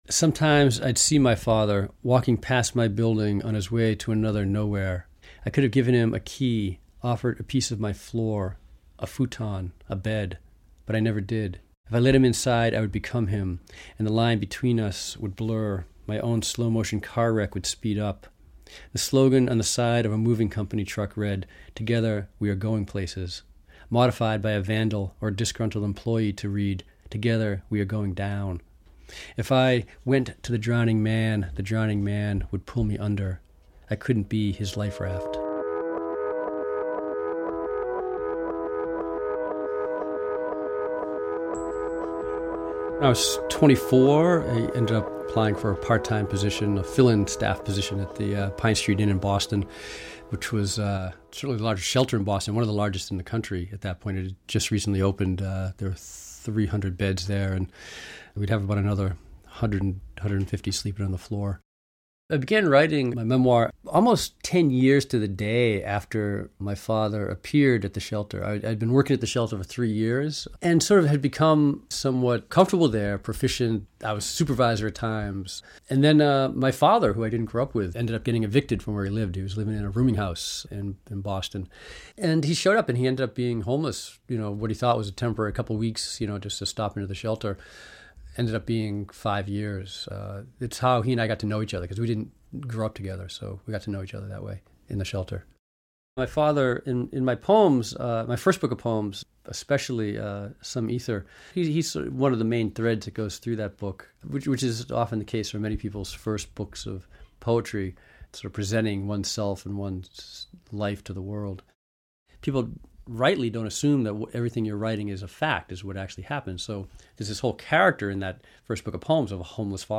Writer Nick Flynn discusses the heartbreaking inspiration behind his first memoir, which was adapted into the film, "Being Flynn." [2:56]